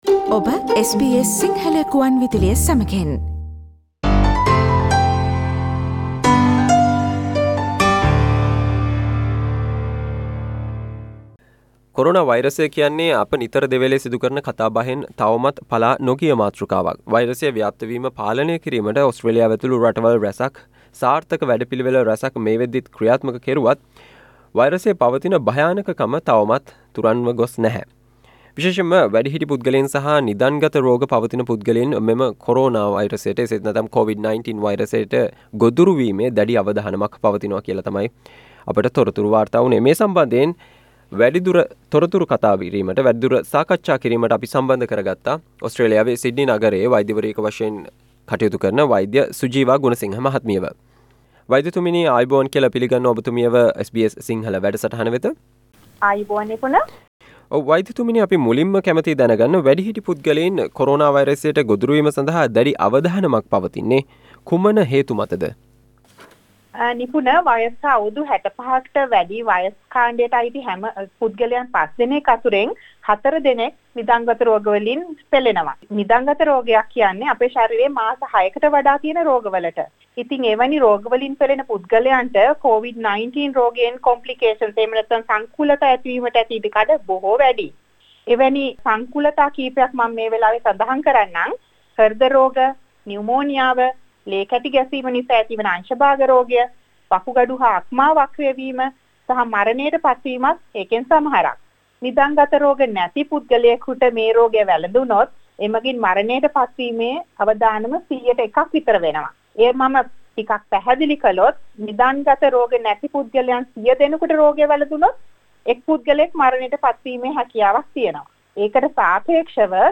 SBS Sinhalese radio discussion